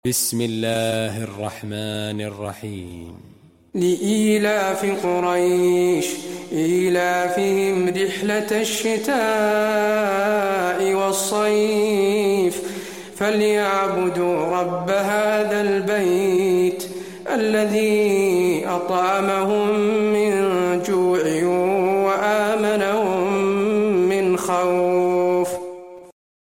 المكان: المسجد النبوي قريش